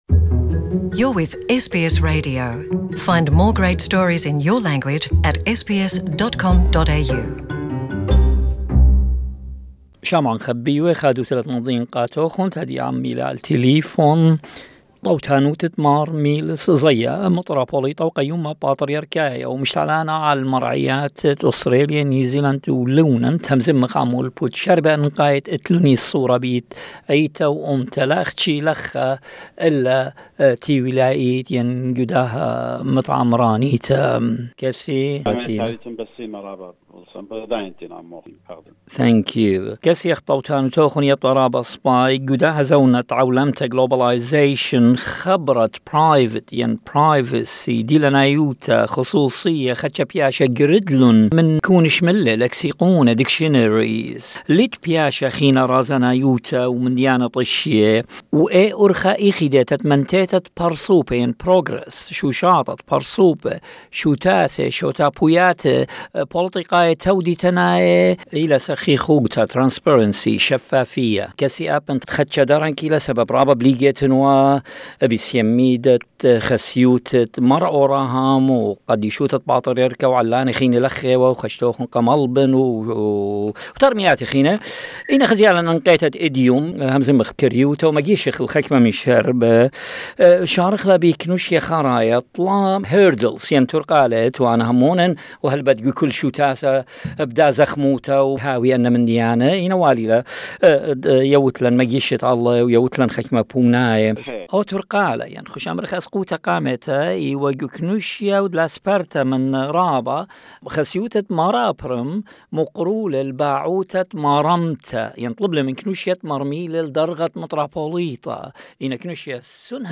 An exclusive interview with H.B Mar Meelis Zaia AM, the Assyrian Church of the East's Metropolitan of Australia, New Zealand and Lebanon. speaking about the, Holy Synod of the Assyrian Church of the East, Mar Aprim, Mar Isaac and Assyrian Church of the East Relief Organisation.(ACERO)